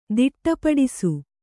♪ diṭṭa paḍisu